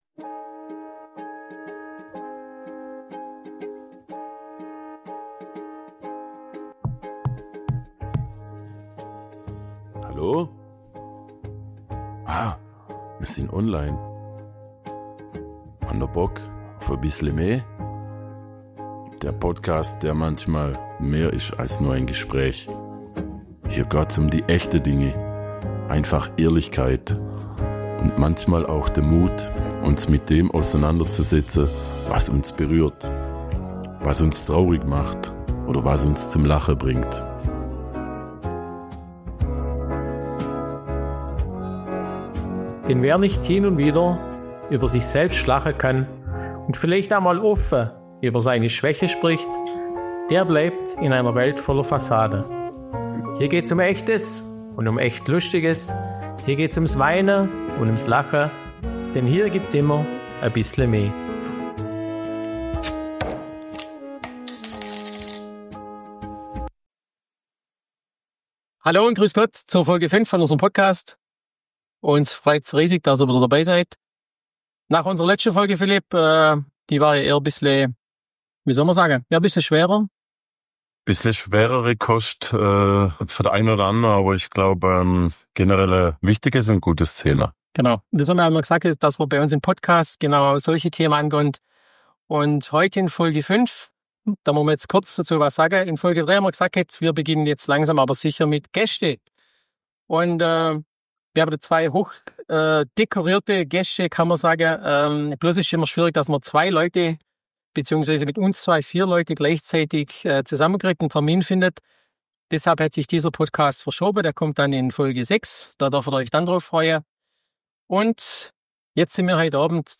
#05 Wir haben keinen Plan – aber einen Gast! ~ Bissle me – Schwoba-Podcast aus´m Schlofsack Podcast
Wir haben nämlich spontan jemanden aus unserer Instagram-Community eingeladen. Ganz ohne Vorwarnung, ganz ohne Drehbuch – einfach echt.